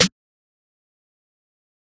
DRO SNARE -24b.wav